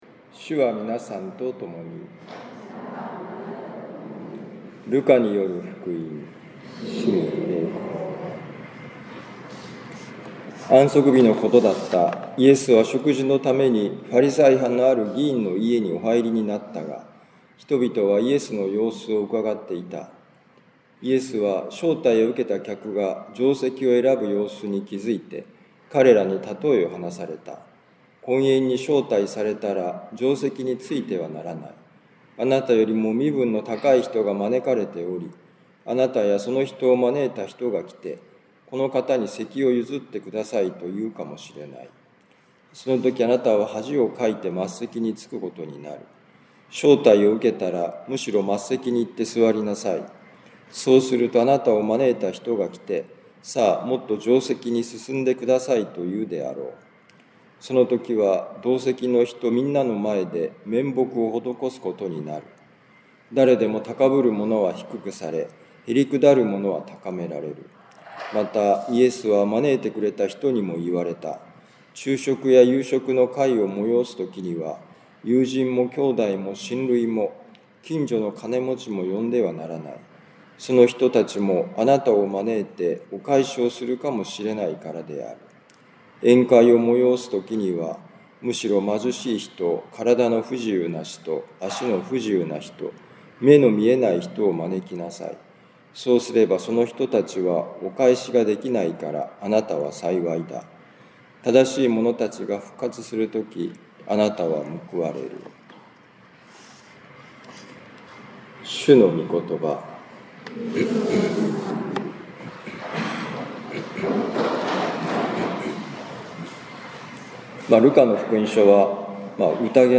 ルカ福音書14章1、7-14節「神の国の神秘」2025年8月31日年間第22主日ミサカトリック長府教会